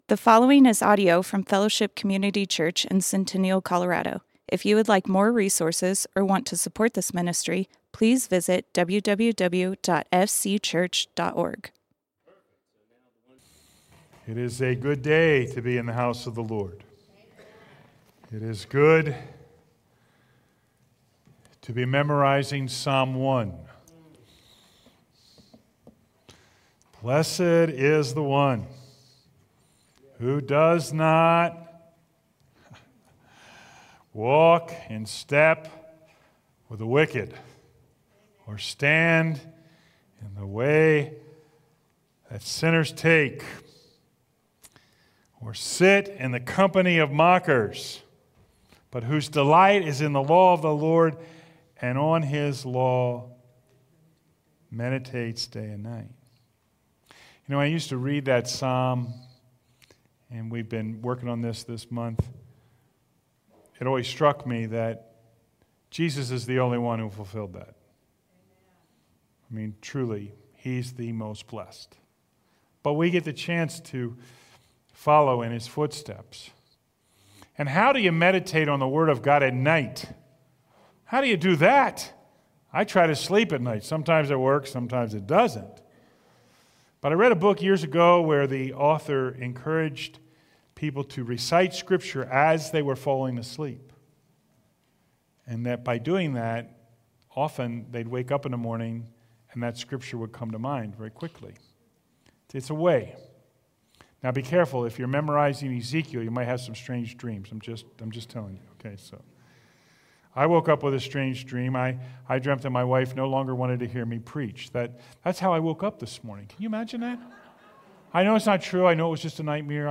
Fellowship Community Church - Sermons Signs of the Season Play Episode Pause Episode Mute/Unmute Episode Rewind 10 Seconds 1x Fast Forward 30 seconds 00:00 / 39:54 Subscribe Share RSS Feed Share Link Embed